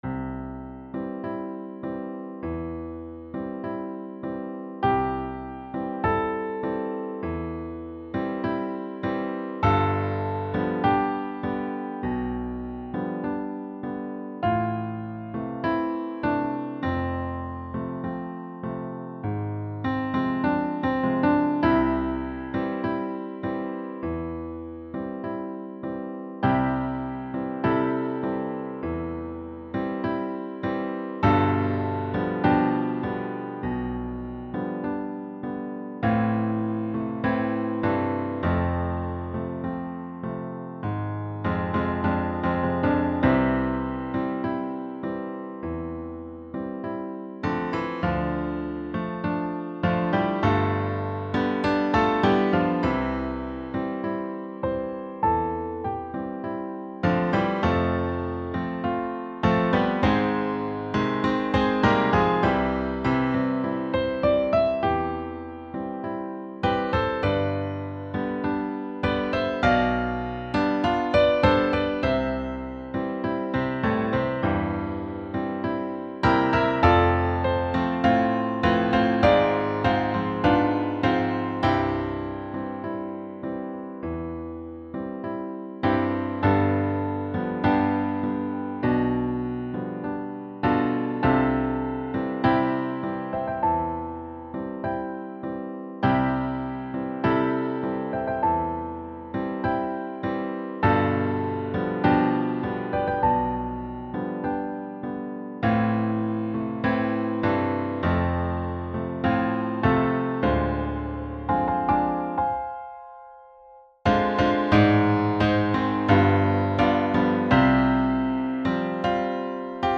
• Music Type: Choral
• Voicing: SATB
• Accompaniment: Piano
litany-like setting
idiomatic piano accompaniment